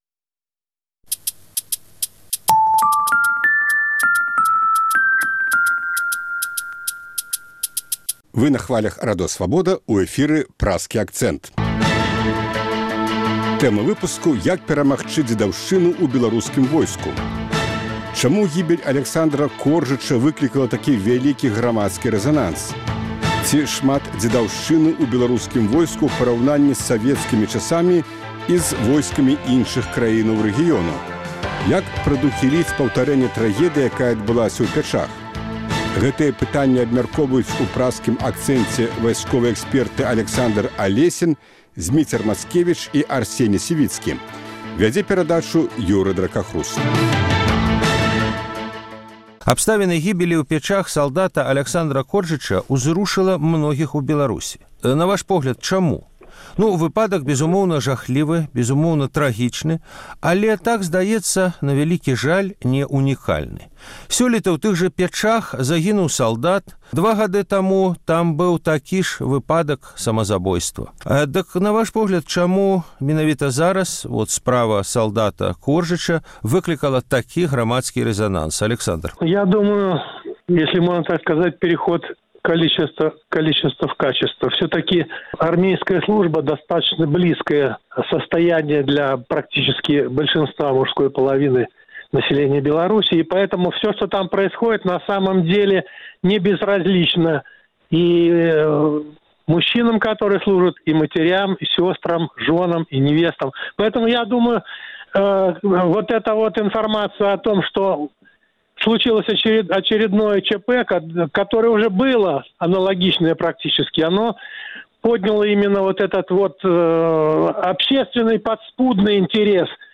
Ці шмат «дзедаўшчыны» ў беларускім войску ў параўнаньні з савецкімі часамі і зь арміямі іншых краінаў рэгіёну? Як прадухіліць паўтарэньне трагедыі, якая адбылася ў Пячах? Гэтыя пытаньні абмяркоўваюць у Праскім акцэнце вайсковыя экспэрты